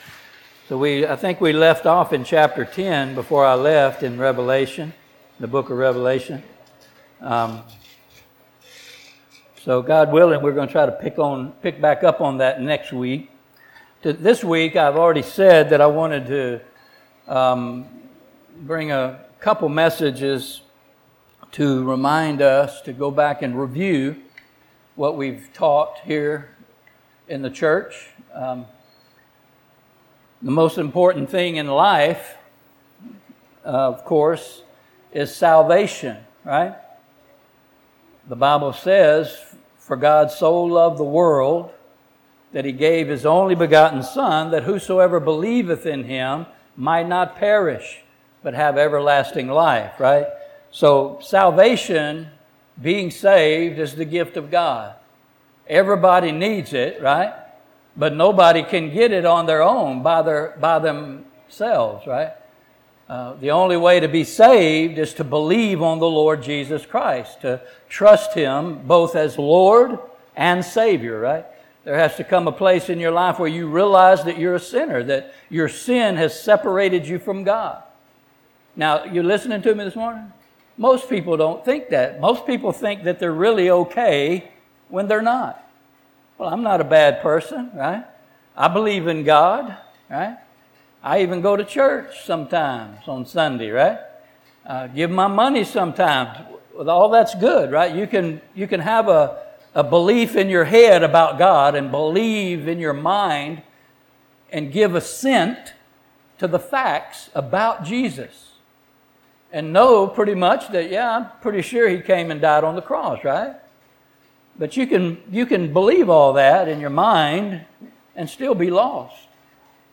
Service Type: Sunday Morning Topics: Gospel , Testimony